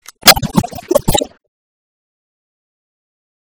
Gun Choke
gun choke.mp3